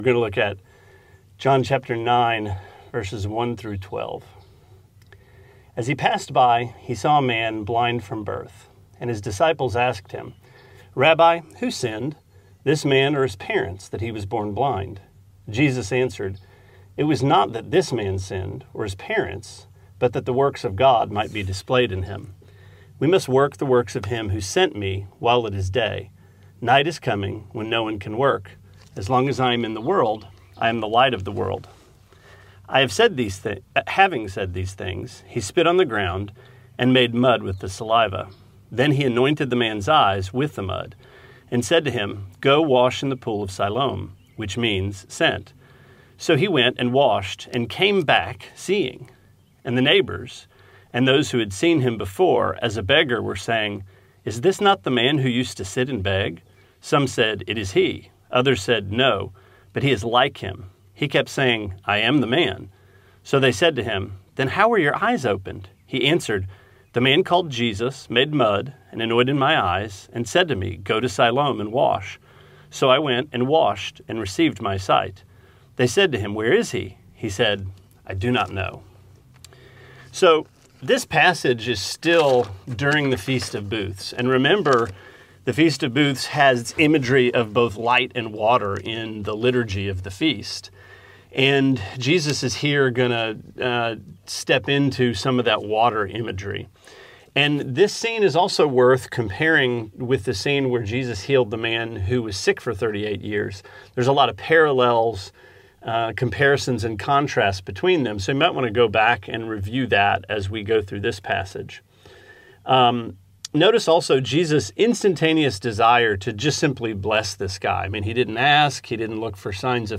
Sermonette 5/16: John 9:1-9: Mudpies